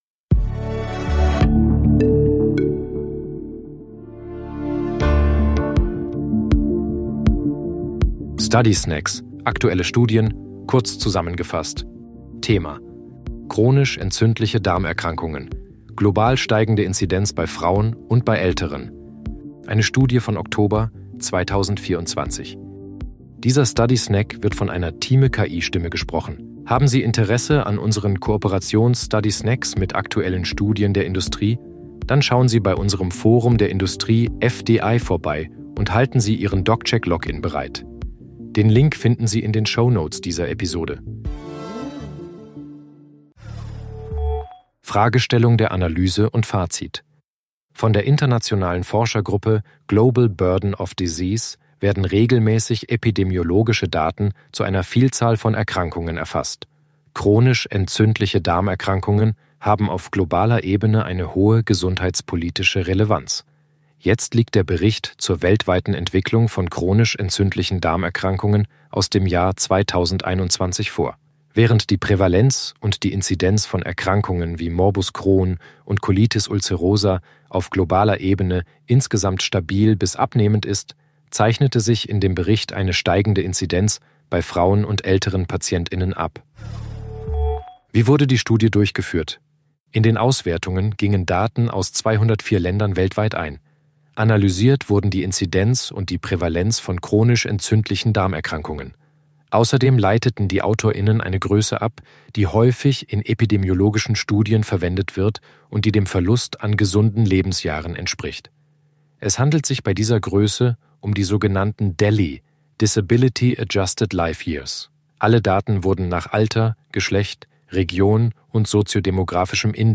Übersetzungstechnologie gesprochene Texte enthalten